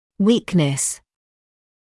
[‘wiːknəs][‘уиːкнэс]слабость; слабое место